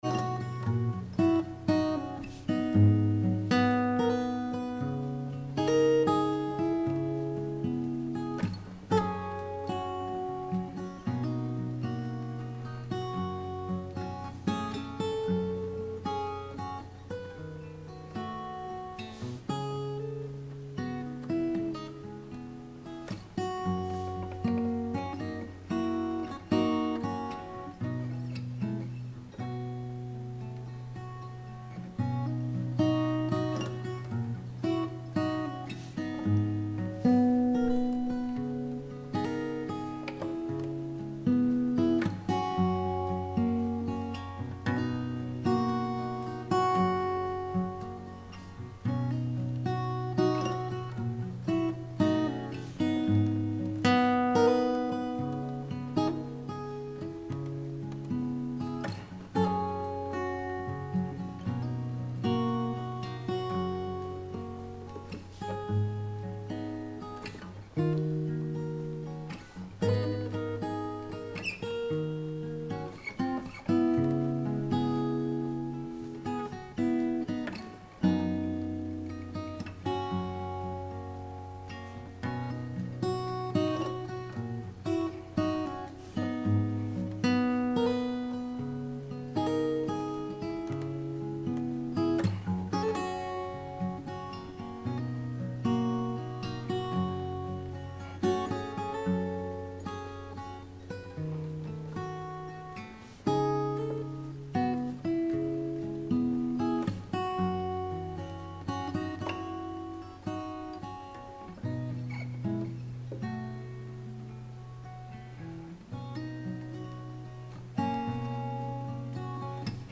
Hello - Fingerstyle Guitar player without any recording skills here :-)
(I am sure the mods can delete it if you object) The level is spot on, around -20dBfs and the playing seems excellent to me (bass players long ago). The recording has a lot of background noise, some of which is a periodic "drone" which you can hear at the very end. I am deaf but the guitar sounds "spikey" to me?